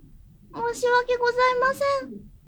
ダウンロード 女性_「申し訳ございません」
キュート女性挨拶